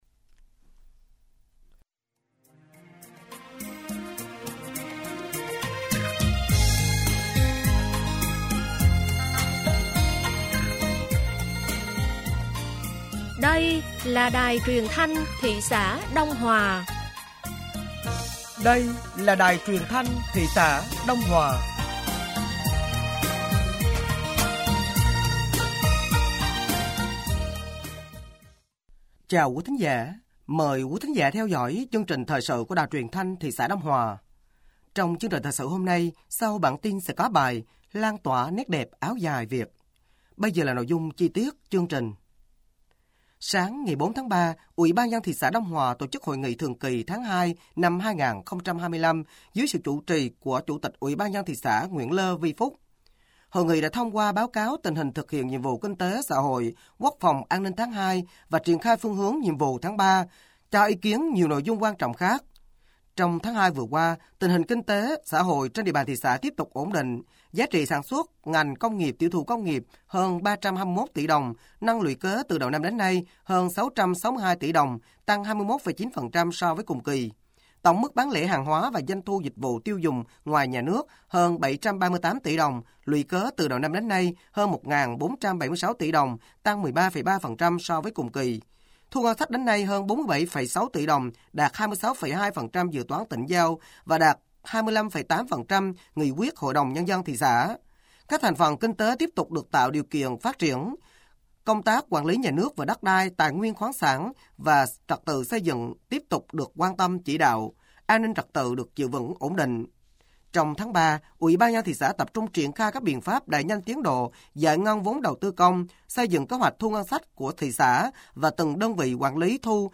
Thời sự tối ngày 04 và sáng ngày 05 tháng 3 năm 2025